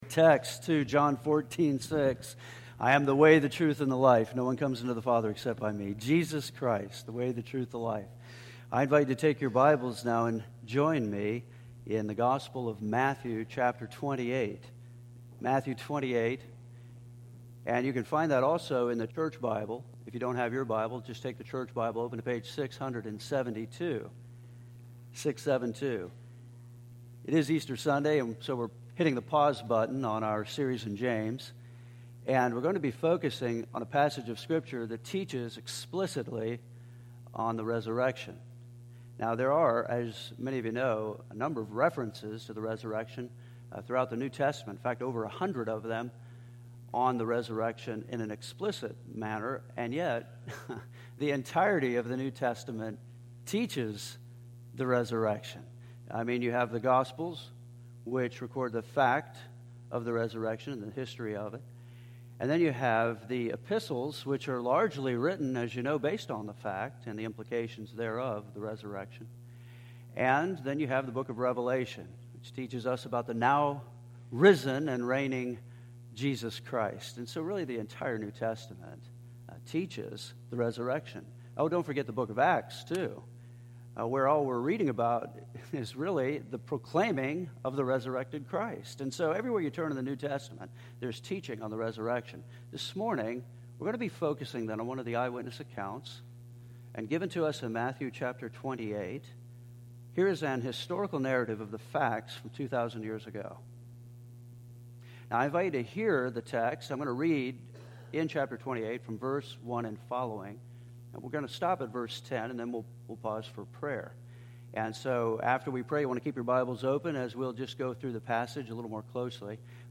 Easter Sunday 2015